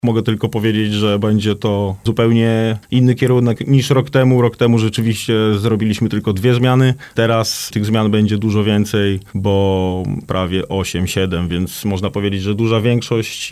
podczas rozmowy w naszym programie „Dookoła Sportu”